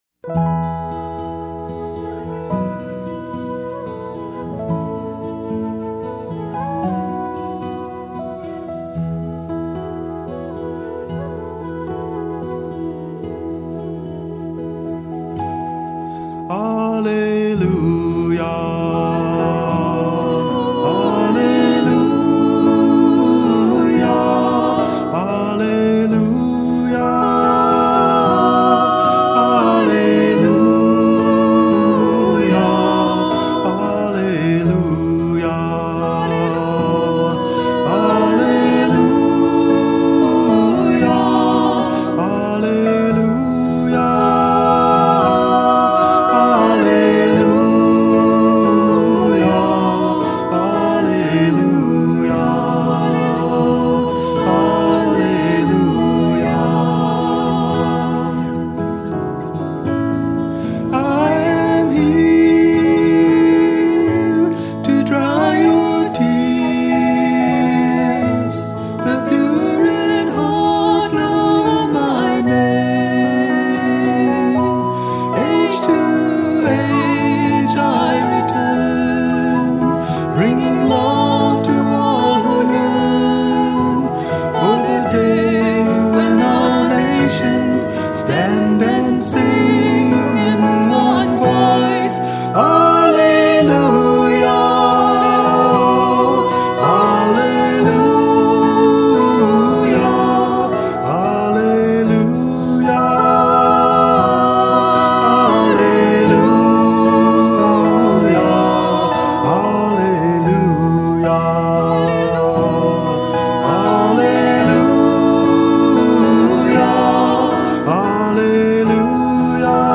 1. Devotional Songs
Major (Shankarabharanam / Bilawal)
8 Beat / Keherwa / Adi
Slow
3 Pancham / E
7 Pancham / B